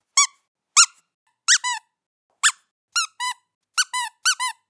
Dog Toy
Category 🐾 Animals
animal cat childs-toy dog Dog funny play squeal sound effect free sound royalty free Animals